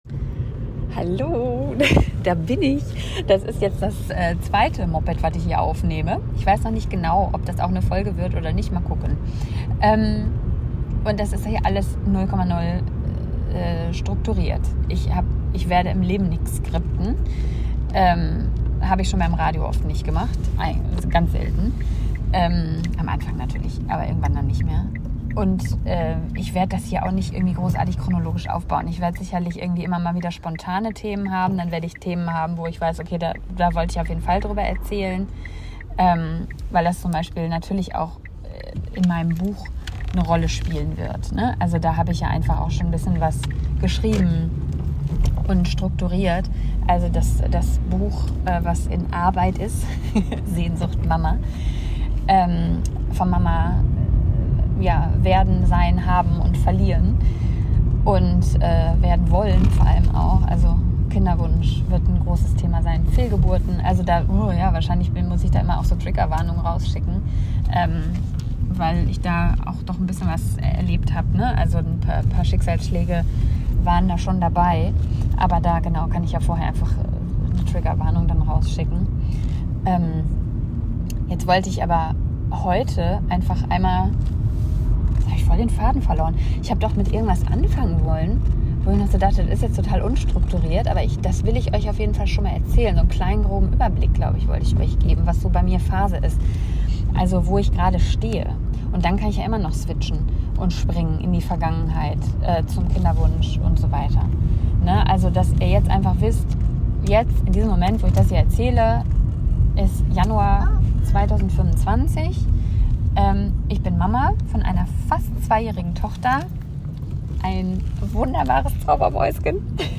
Blinker und kurzes Gemotze inklusive:-)